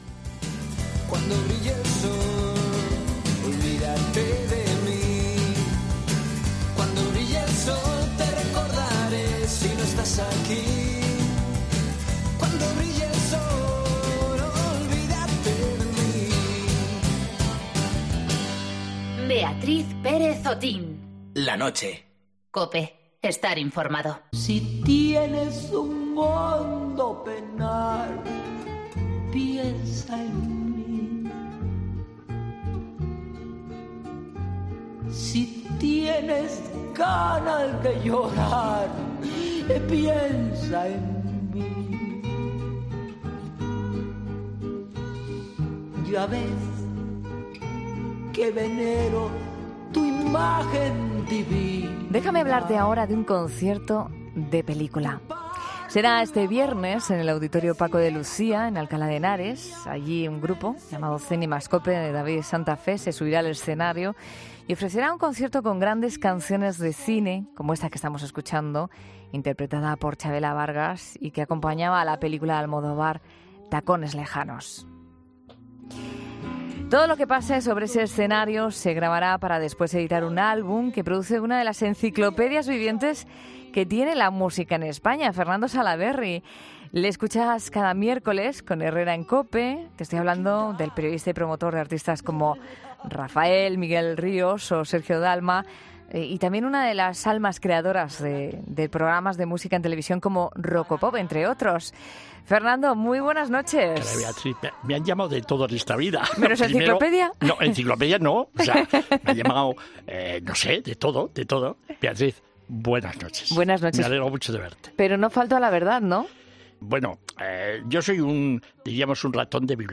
Entrevistamos al periodista